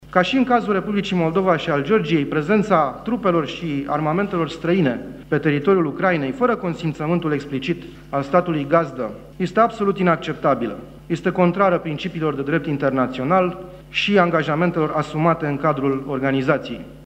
Într-un discurs rostit la sediul MAE, șeful diplomației române a avertizat că declarațiile de acest fel pot fi calificate ca fiind provocări.